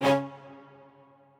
strings1_23.ogg